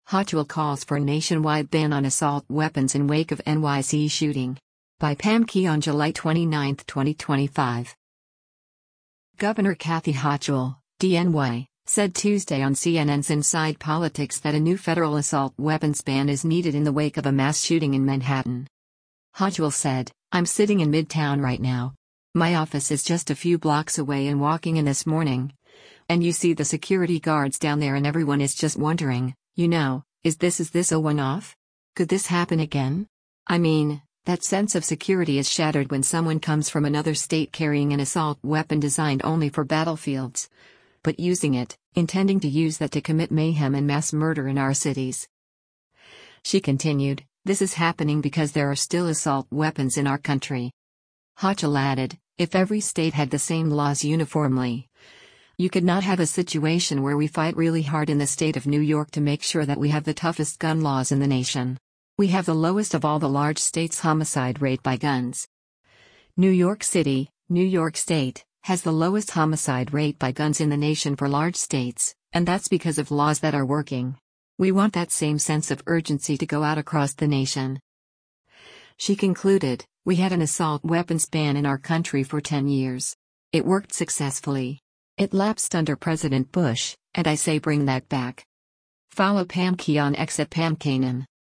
Governor Kathy Hochul (D-NY) said Tuesday on CNN’s “Inside Politics” that a new federal assault weapons ban is needed in the wake of a mass shooting in Manhattan.